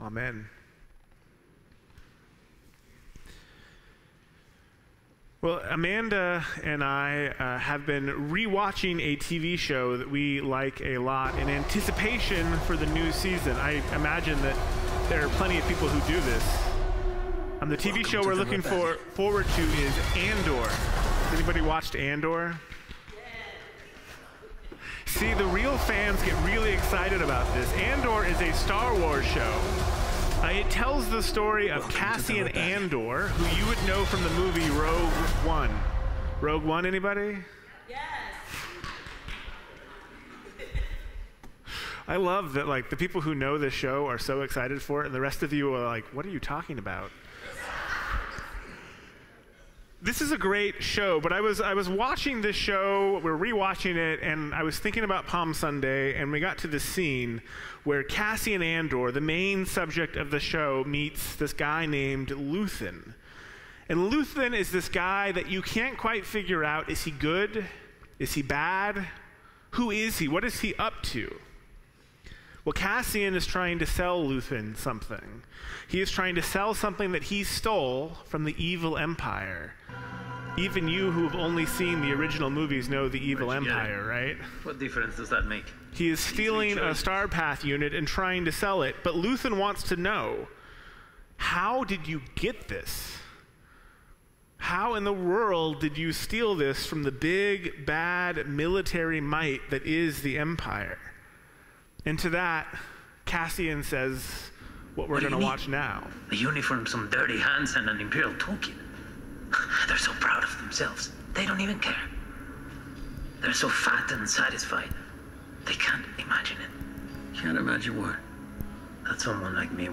Sermons | First Christian Church